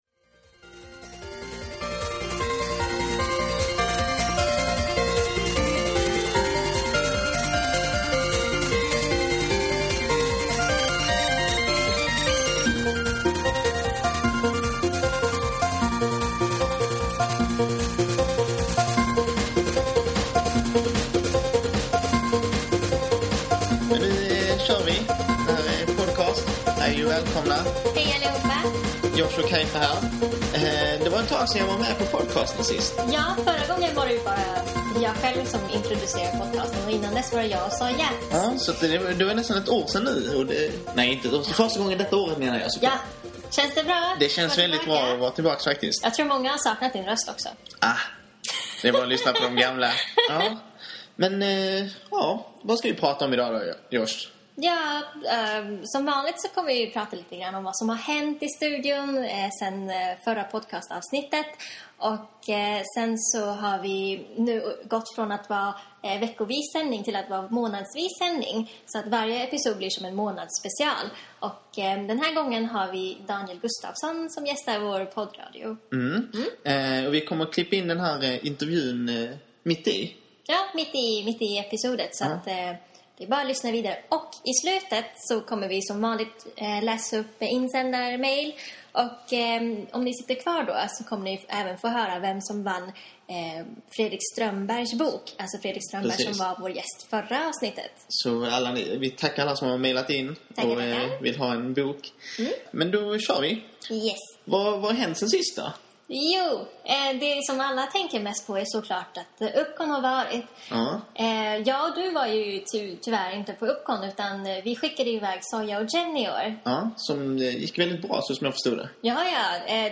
The staff of Yokaj Studio, Sweden's first and most established manga studio, discuss news and give you updates about the studio, the Swedish manga market, bits and bobs of the manga culture in Sweden and more!